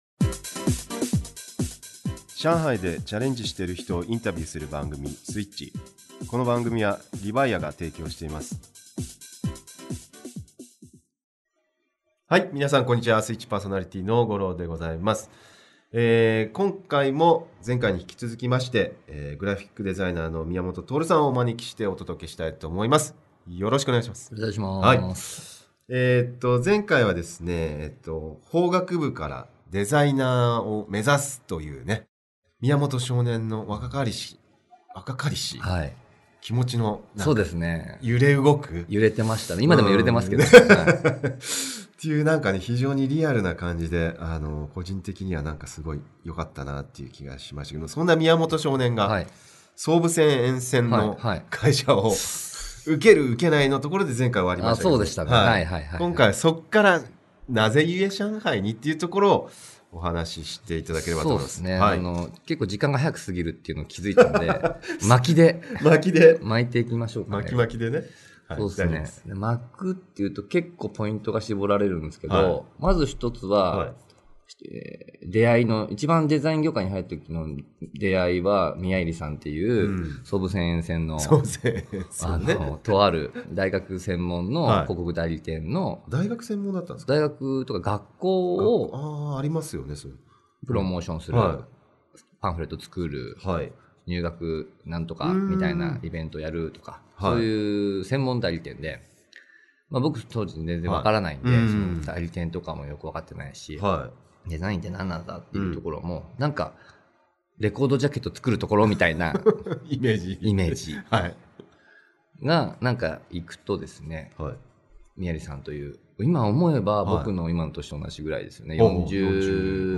【Switchインタビュー第93回】